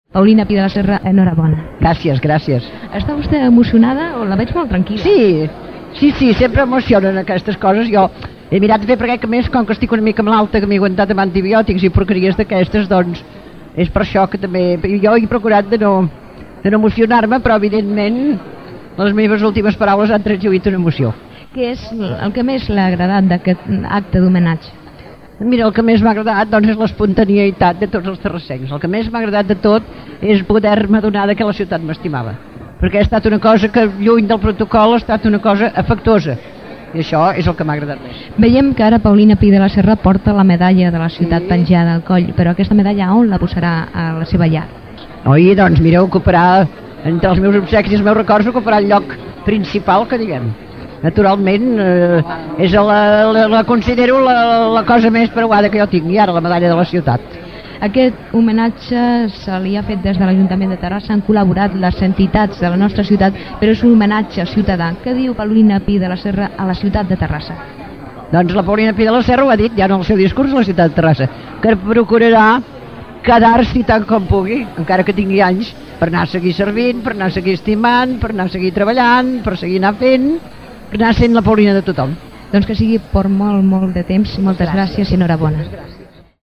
Àudios: arxiu històric de Ràdio Terrassa